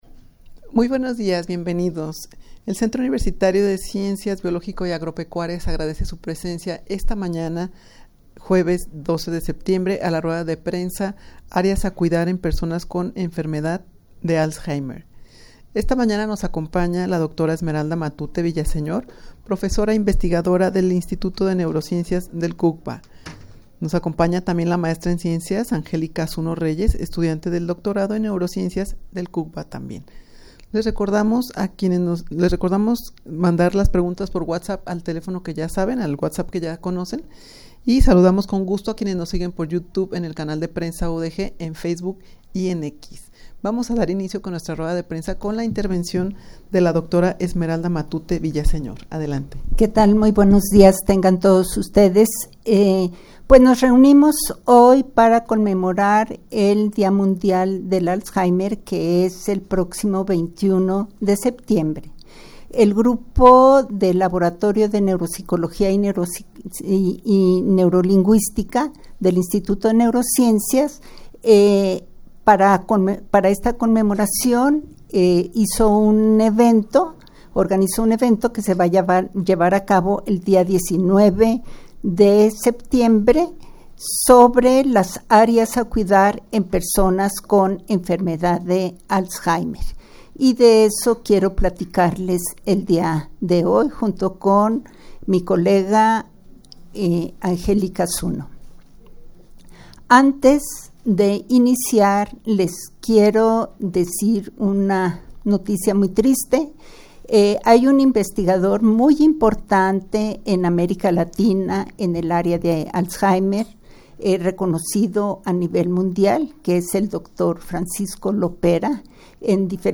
rueda-de-prensa-areas-a-cuidar-en-personas-con-enfermedad-de-alzheimer.mp3